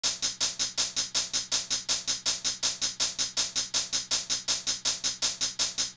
kits/Cardiak/Closed Hats/Hihats.wav at main
Hihats.wav